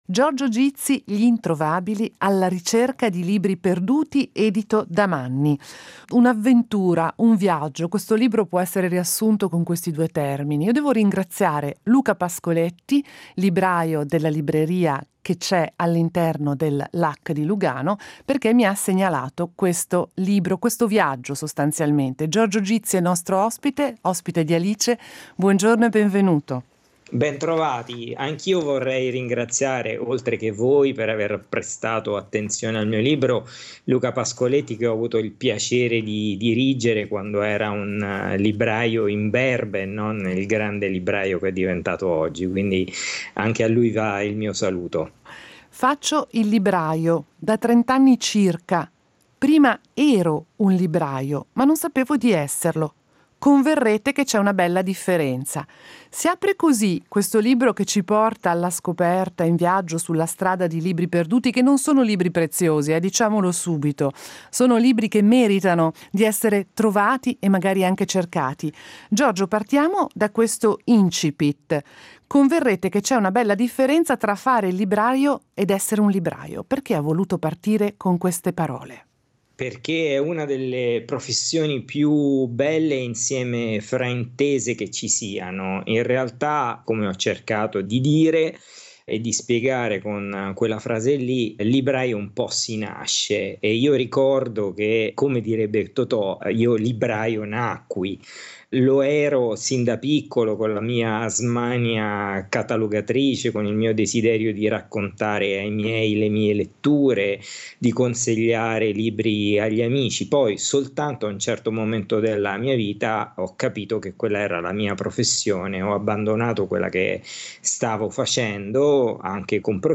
al microfono